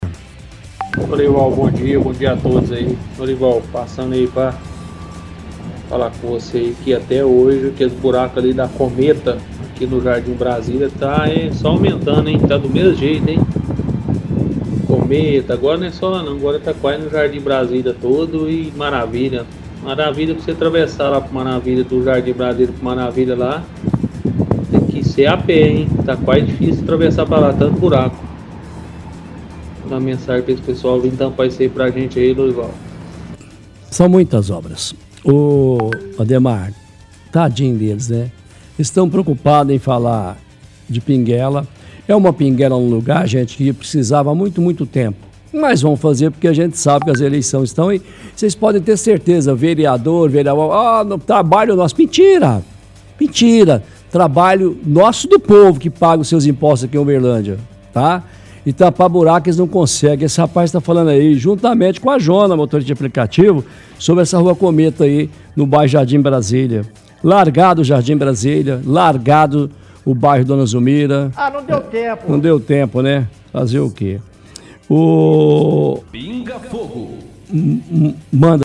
– Ouvinte reclama de buraco da R. Cometa no bairro Jardim Brasília, fala que até hoje o buraco não foi tampado. Cita que buraco está só aumentando de tamanho.